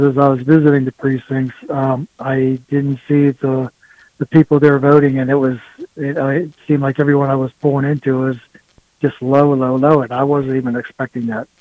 Indiana County Commissioner Mike Keith said he was surprised by that total after what he saw when he checked in at several precincts.